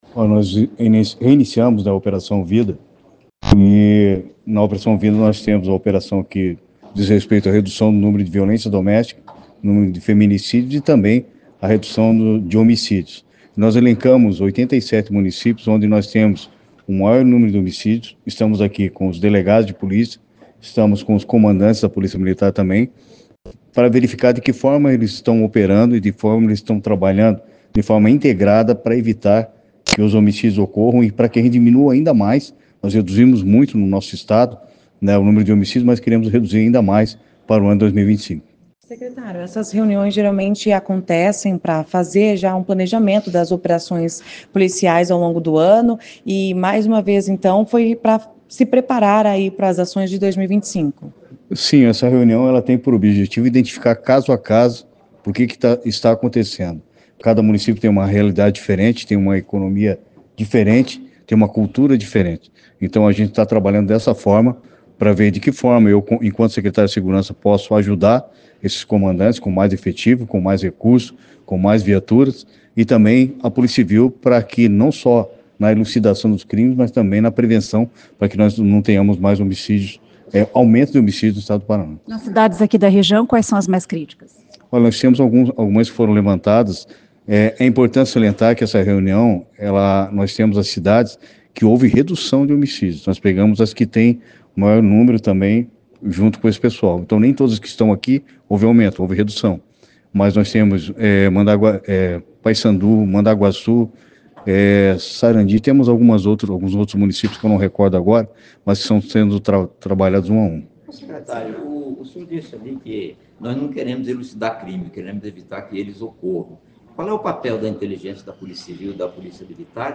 Ouça o que diz o secretário de Segurança Pública do Paraná, Hudson Teixeira.